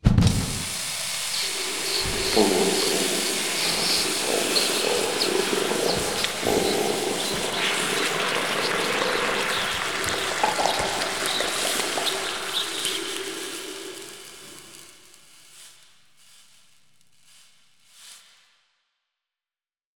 sfx updates